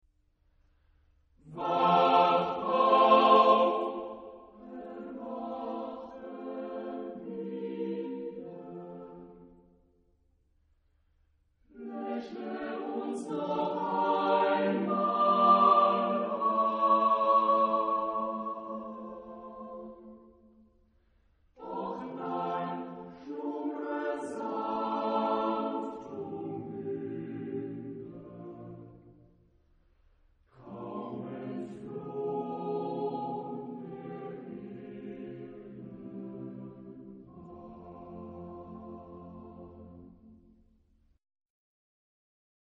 Género/Estilo/Forma: Profano ; Poema ; Romántico
Carácter de la pieza : sombrío ; calma
Tipo de formación coral: SATB  (4 voces Coro mixto )
Tonalidad : fa mayor
Consultable bajo : Romantique Profane Acappella